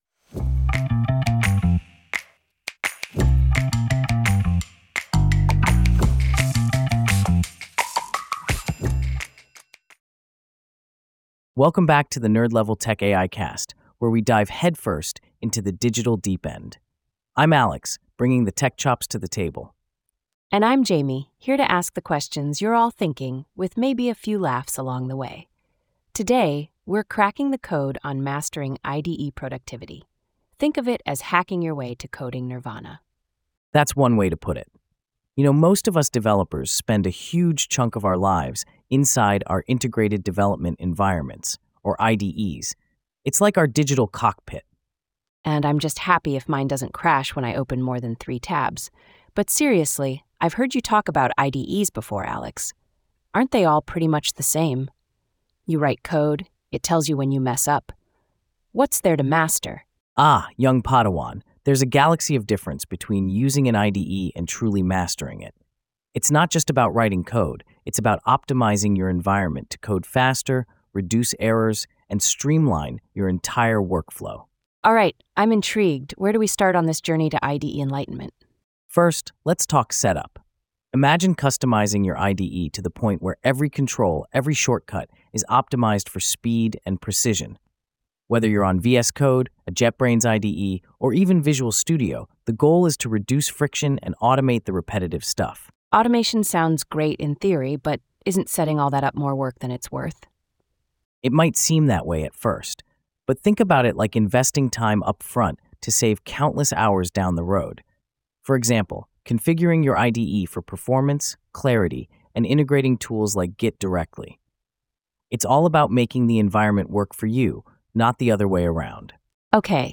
AI-generated discussion by Alex and Jamie